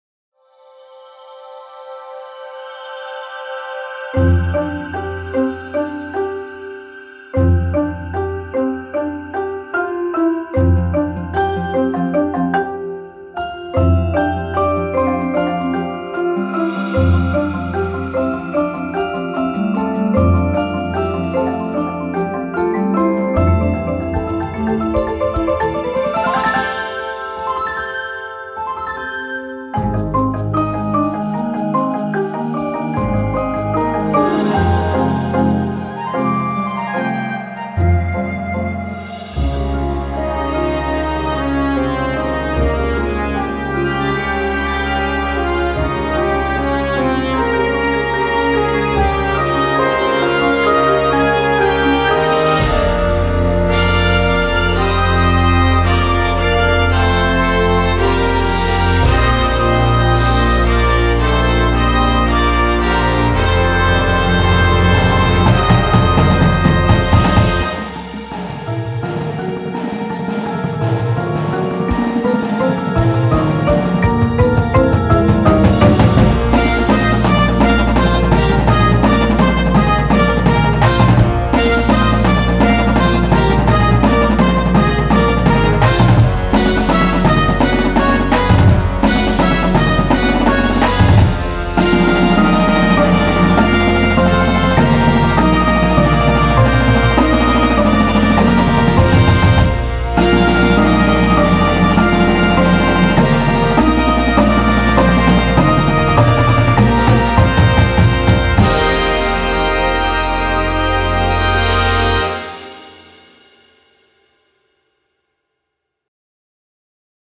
Marching Band Shows
and the swing of a big band sound